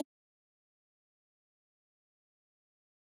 button-pressed.ogg